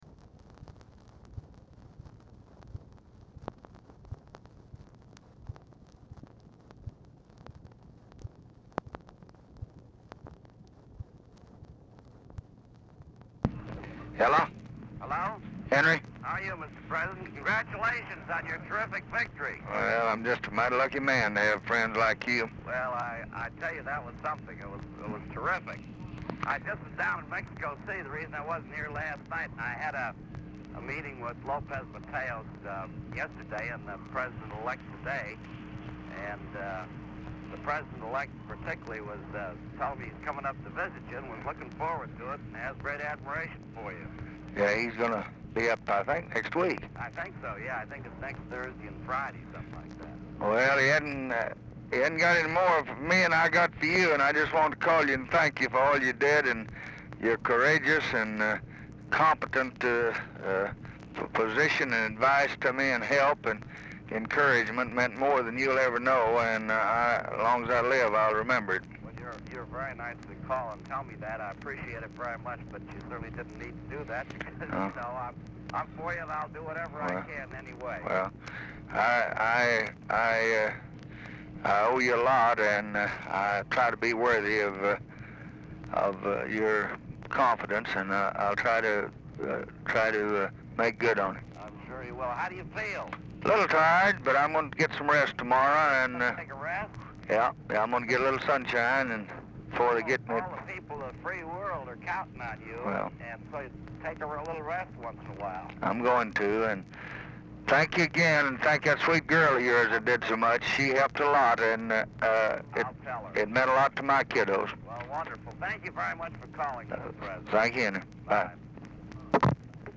Telephone conversation # 6253, sound recording, LBJ and HENRY FORD II, 11/5/1964, 10:21PM | Discover LBJ
Format Dictation belt
Location Of Speaker 1 LBJ Ranch, near Stonewall, Texas
Specific Item Type Telephone conversation